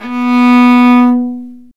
Index of /90_sSampleCDs/Roland - String Master Series/STR_Viola Solo/STR_Vla3 Arco nv
STR VIOLA 08.wav